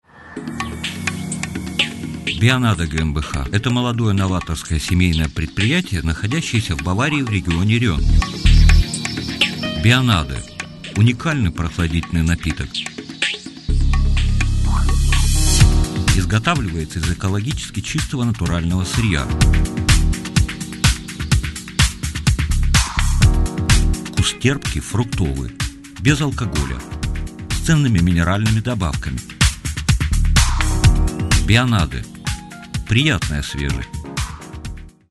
RUSSISCHER PROFI-SPRECHER, Hochrussisch, Akzentfrei, Muttersprache.
Sprechprobe: Werbung (Muttersprache):
russian voice over artist